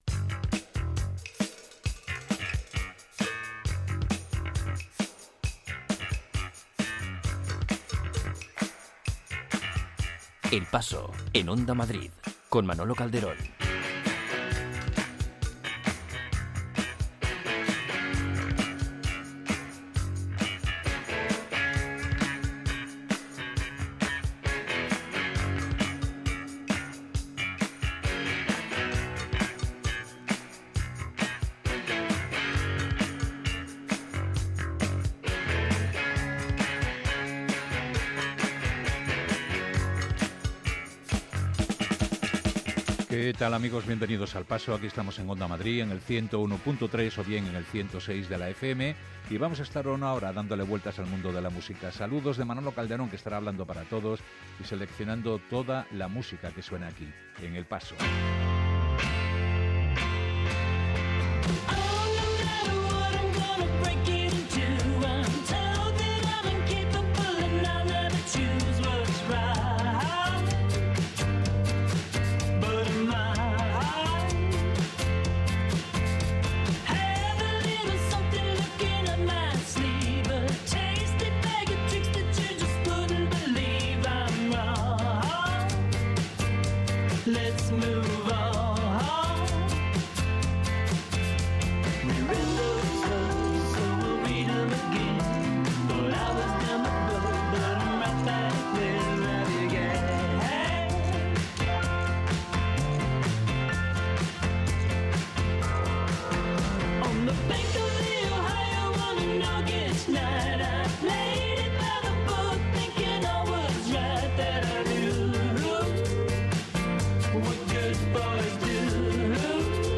No hay década mala en el repaso musical que hace El Paso, desde el primigenio rock de los años 50 hasta el blues contemporáneo, pasando por el beat, power pop, surf, punk, rock o música negra.